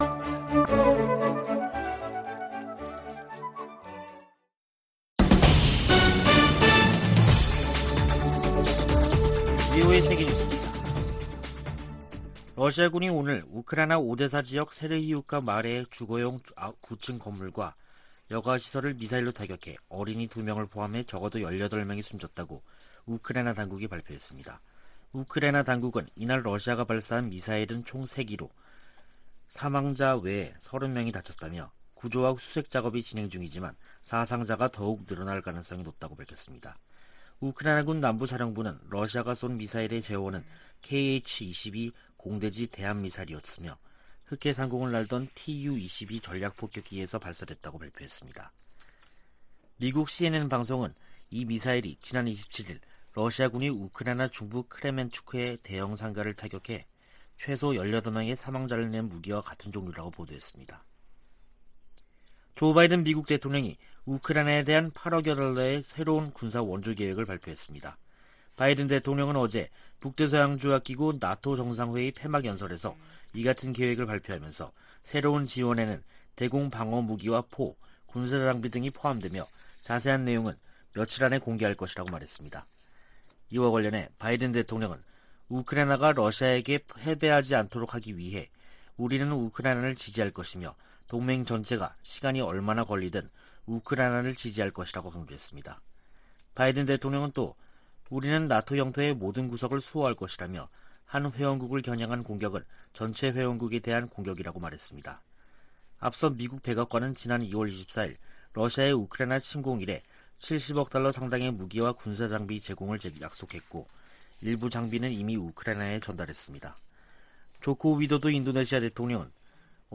VOA 한국어 간판 뉴스 프로그램 '뉴스 투데이', 2022년 7월 1일 3부 방송입니다. 북대서양조약기구(NATO·나토) 정상회의가 막을 내린 가운데 조 바이든 미국 대통령은 ‘역사적’이라고 평가했습니다. 미국의 한반도 전문가들은 윤석열 한국 대통령이 나토 정상회의에서 북핵 문제에 대한 미한일 3각협력 복원 의지를 분명히했다고 평가했습니다. 미 국무부가 미일 동맹 현대화와 미한일 삼각공조 강화 등 일본 전략을 공개했습니다.